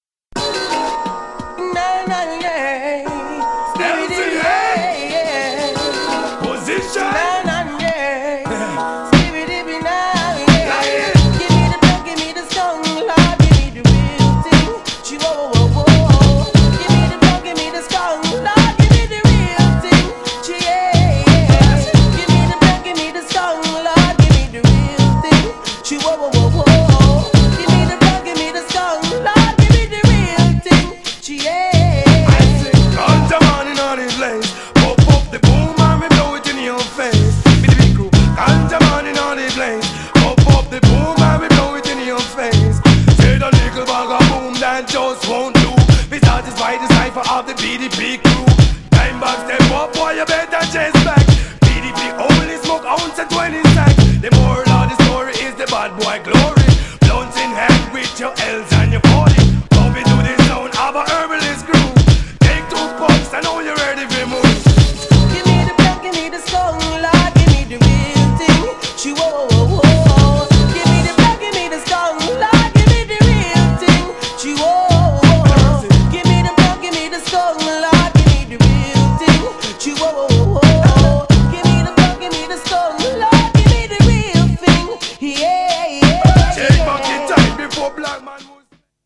GENRE Hip Hop
BPM 86〜90BPM